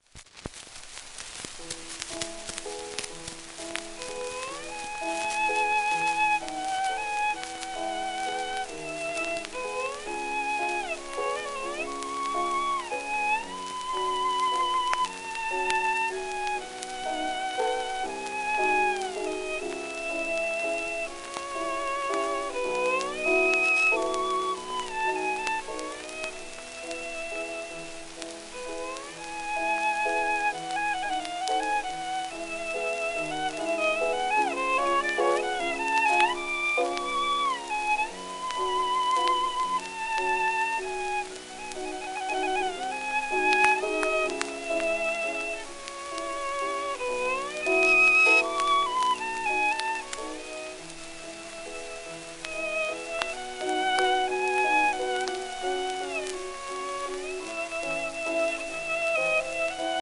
w/piano
盤質A-/B+ *サーフェイスノイズ,キズ
旧 旧吹込みの略、電気録音以前の機械式録音盤（ラッパ吹込み）